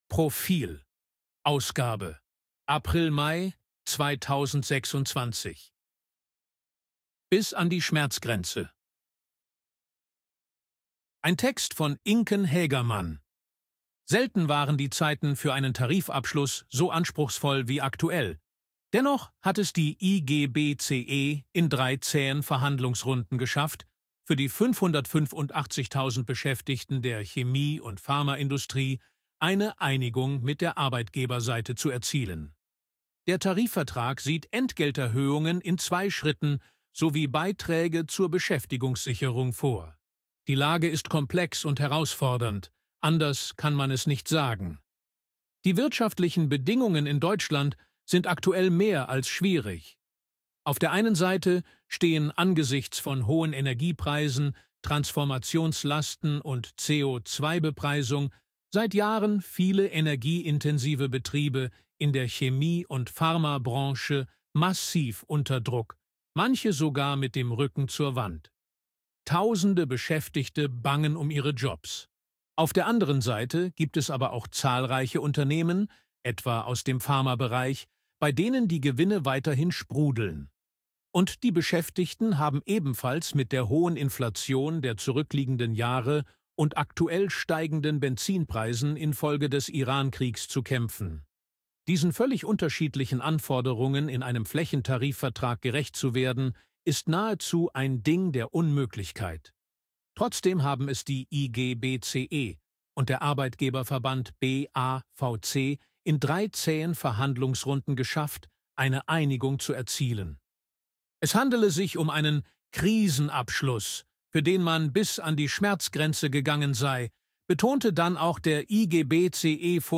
Artikel von KI vorlesen lassen ▶ Audio abspielen
ElevenLabs_262_KI_Stimme_Mann_HG-Story.ogg